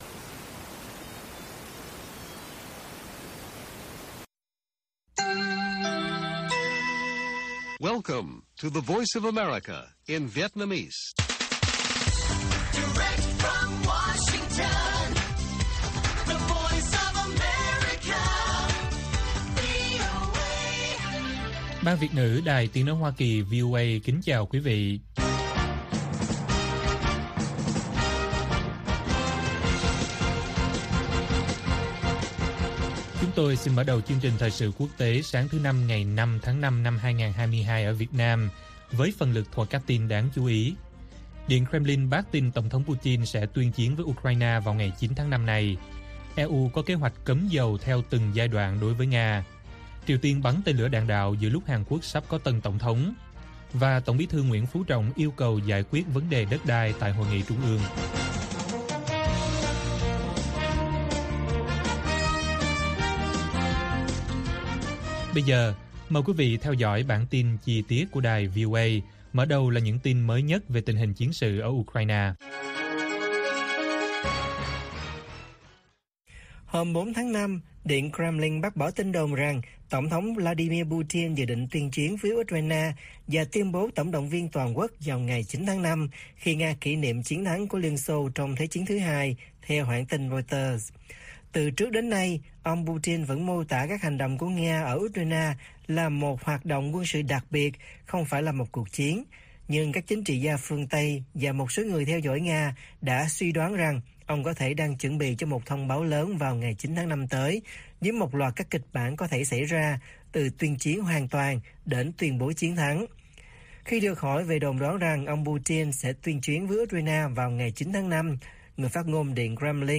Điện Kremlin bác tin Putin sẽ tuyên chiến với Ukraine vào ngày 9/5 - Bản tin VOA